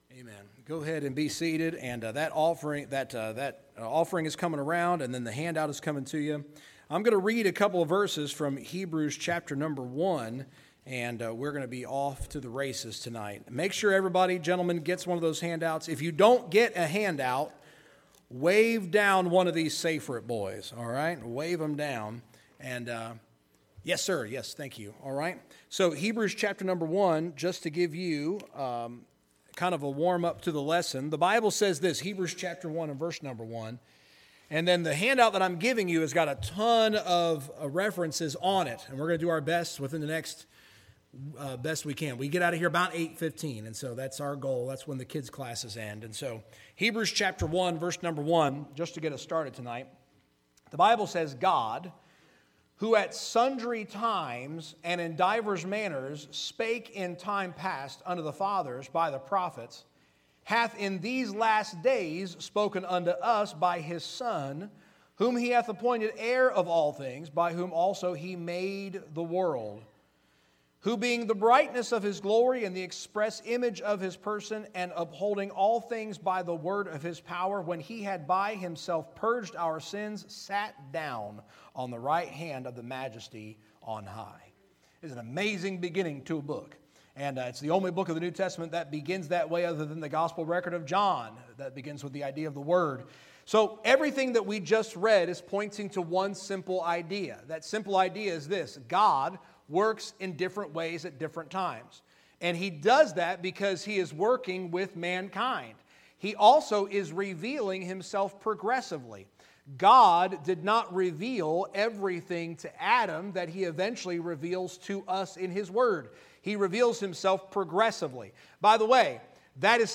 Sermons | Victory Hill Baptist Church